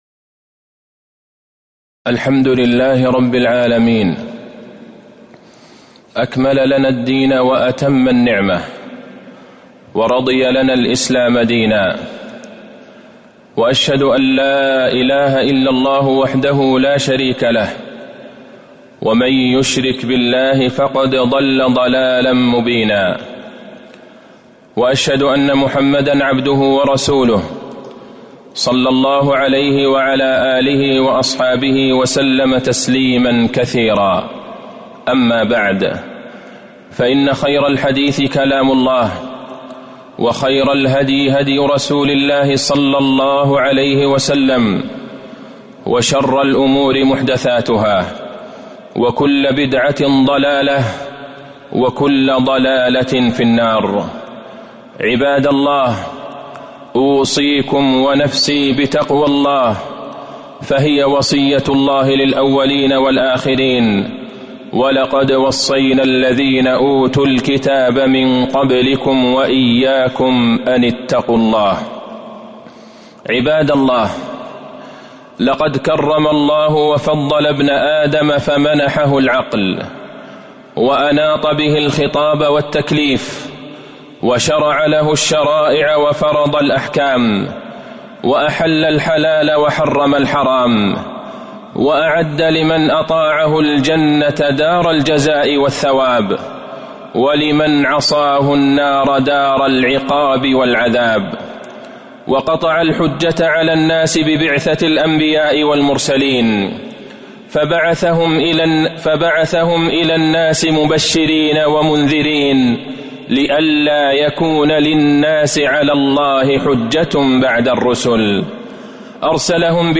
تاريخ النشر ١٦ ربيع الأول ١٤٤٣ هـ المكان: المسجد النبوي الشيخ: فضيلة الشيخ د. عبدالله بن عبدالرحمن البعيجان فضيلة الشيخ د. عبدالله بن عبدالرحمن البعيجان التمسك بالسنة